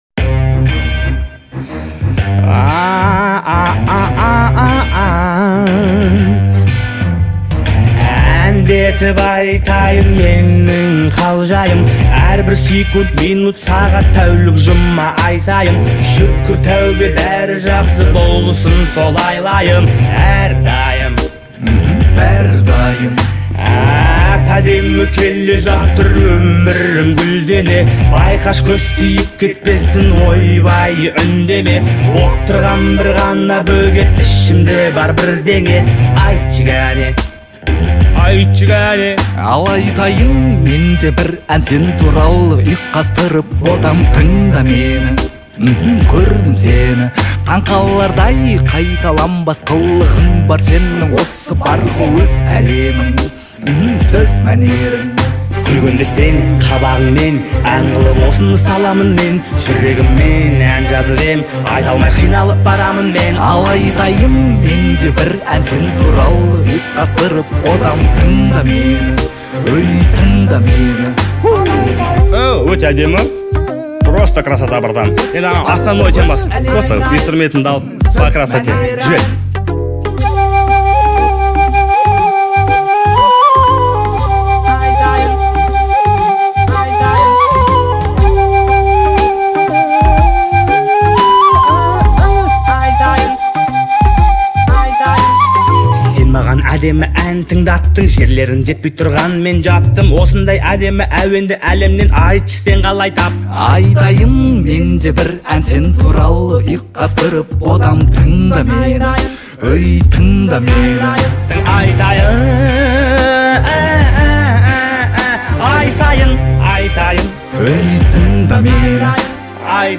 это яркий пример сочетания поп и хип-хоп жанров